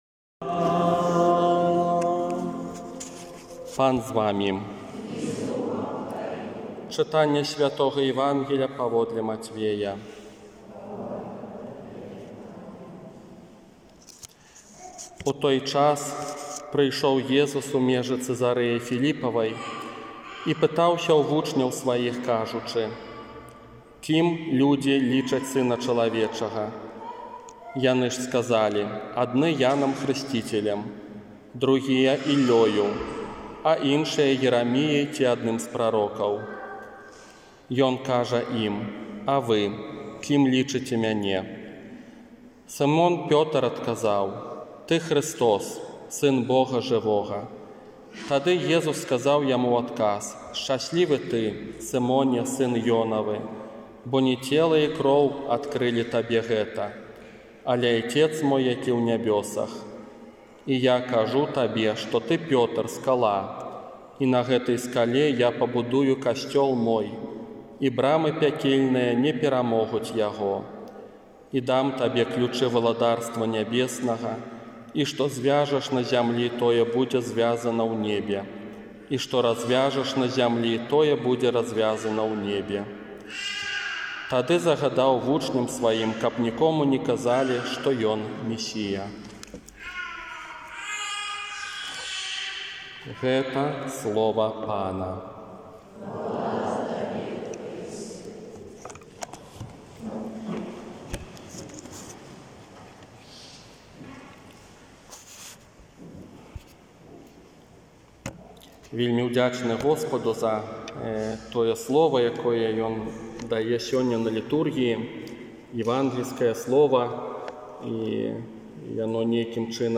Дваццаць першая звычайная нядзеля 23 жніўня 2020 Развітальнае казанне ў Оршы
Хто_Я_для_цябе._казанне_на_развiтанне.m4a